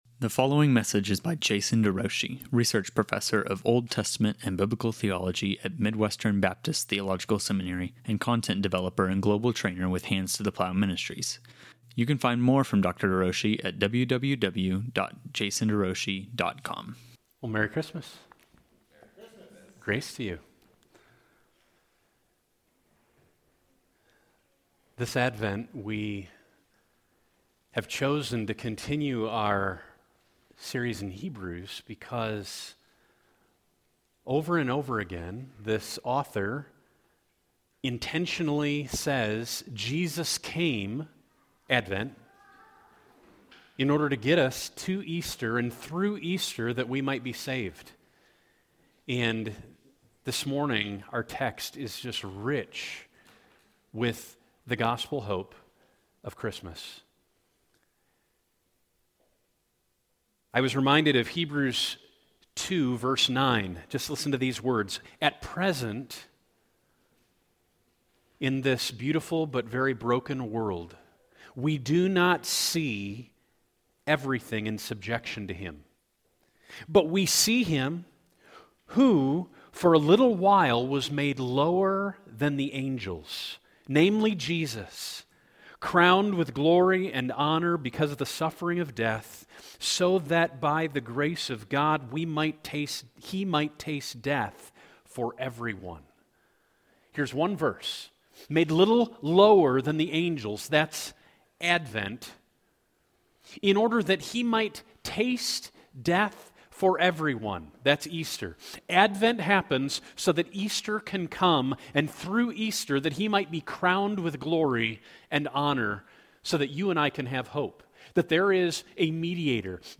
A Sermon on Hebrews 10:1–18